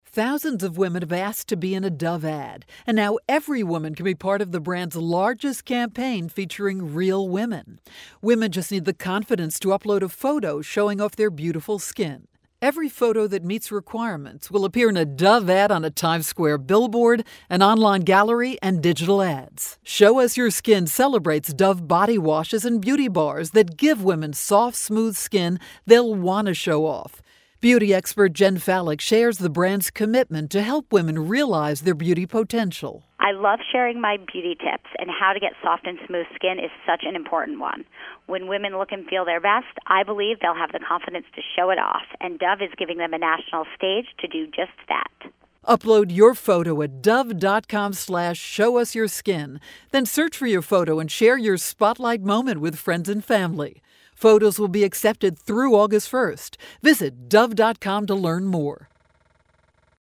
April 19, 2012Posted in: Audio News Release